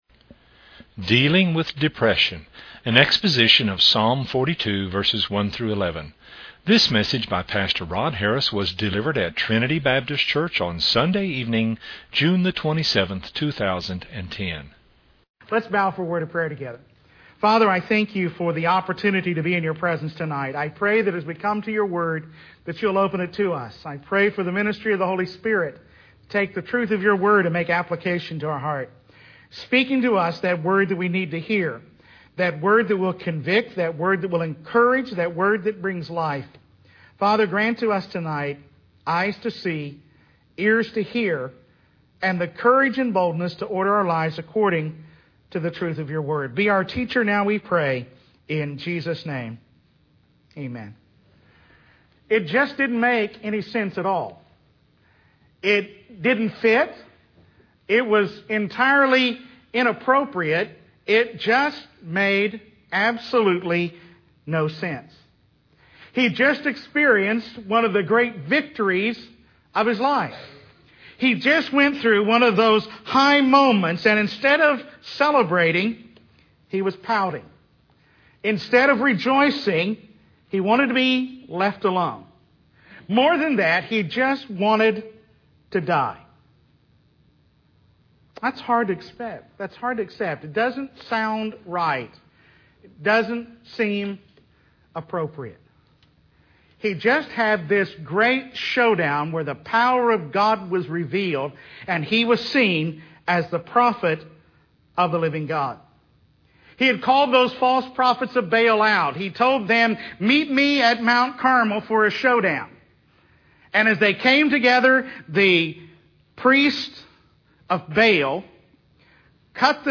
An exposition of Psalm 42:1-11
at Trinity Baptist Church on Sunday evening